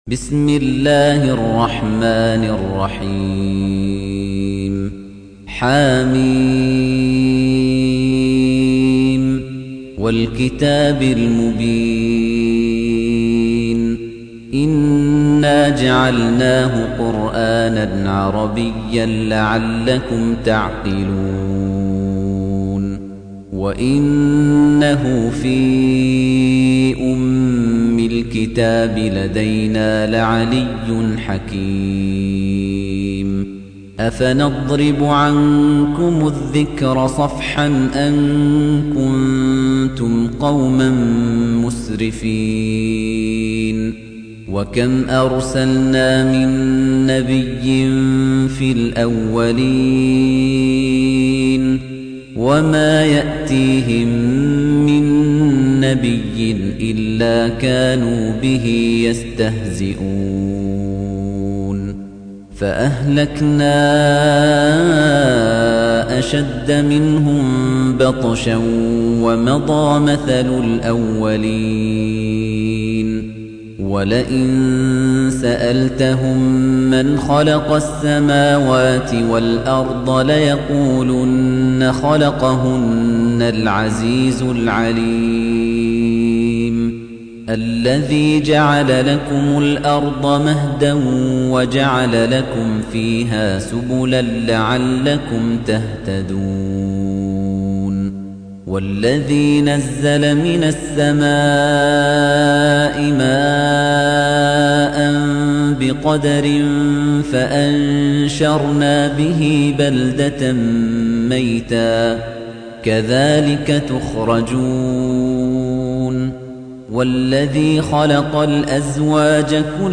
Surah Repeating تكرار السورة Download Surah حمّل السورة Reciting Murattalah Audio for 43. Surah Az-Zukhruf سورة الزخرف N.B *Surah Includes Al-Basmalah Reciters Sequents تتابع التلاوات Reciters Repeats تكرار التلاوات